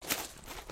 skeleton_walk.ogg